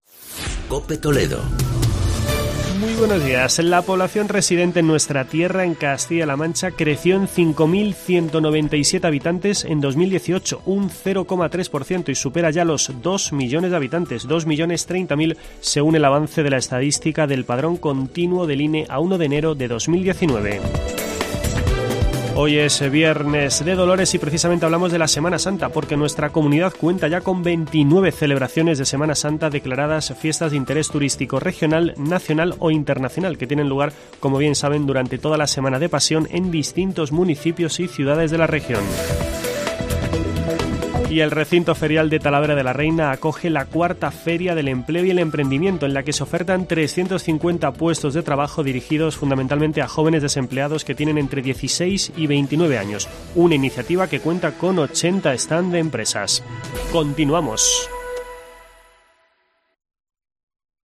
Boletín informativo de la Cadena COPE.